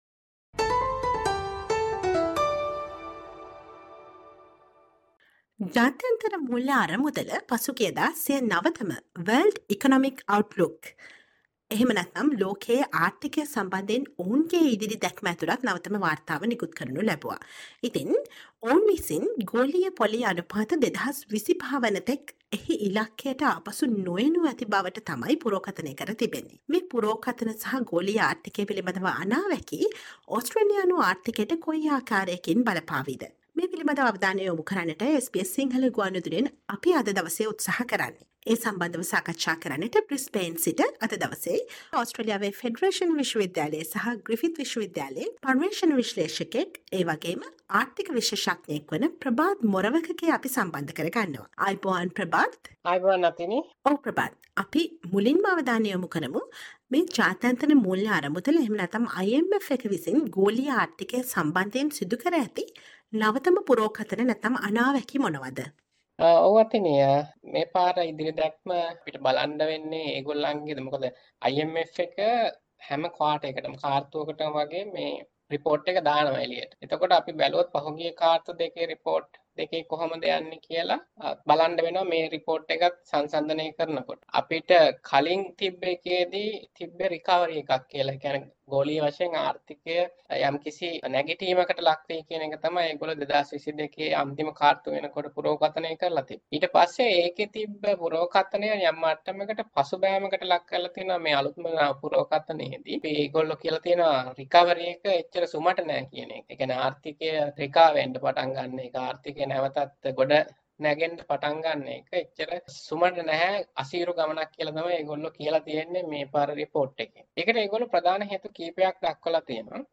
Listen to the SBS Sinhala radio interview on the the newest global economic predictions that IMF has made and What Australian need to expect with the newest global economic trends.